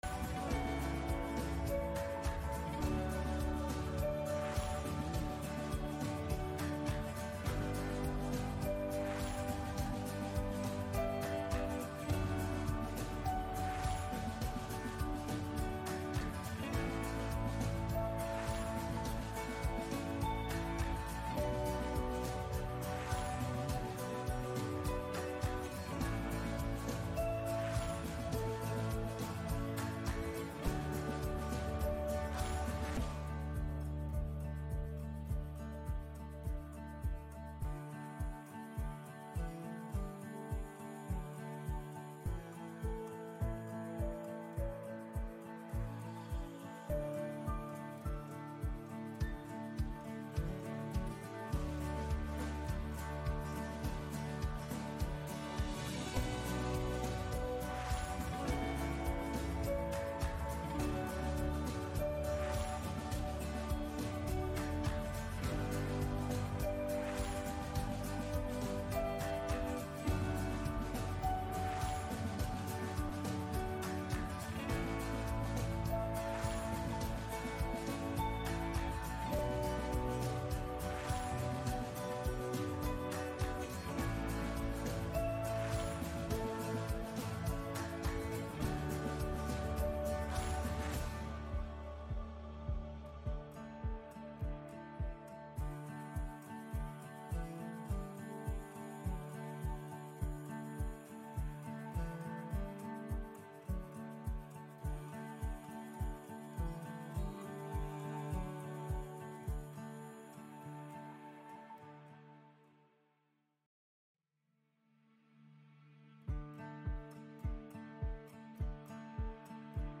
Gottesdienst am 3. Advent aus der Christuskirche Altona